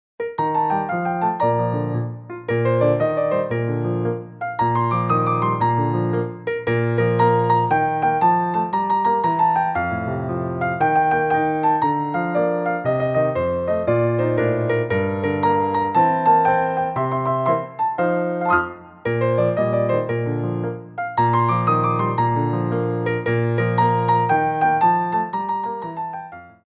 for Ballet Class
Glissés
6/8 (16x8)